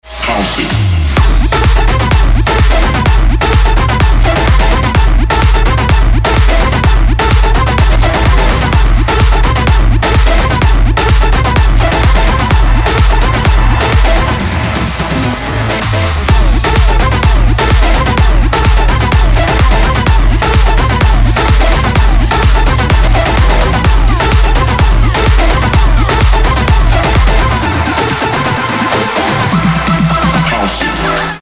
• Качество: 320, Stereo
громкие
dance
без слов
club
Стиль: Electro house